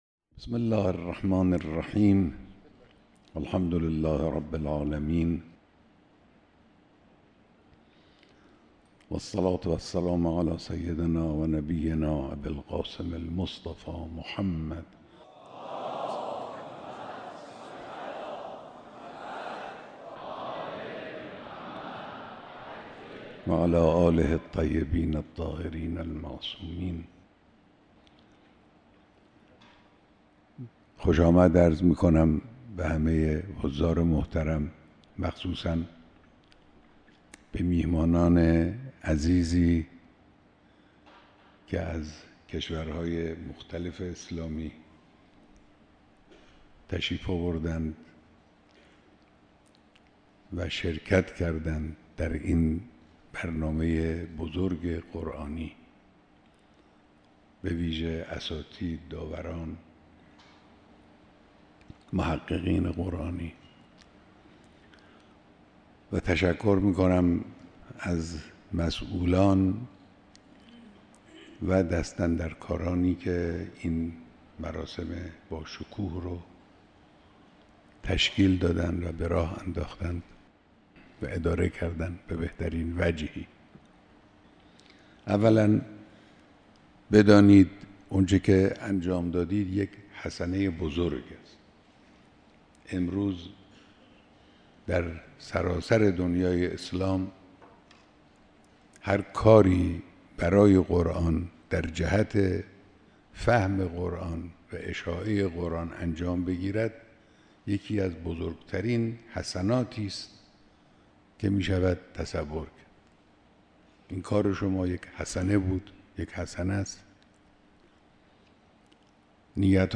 بیانات در دیدار شرکت‌کنندگان در مسابقات بین‌المللی قرآن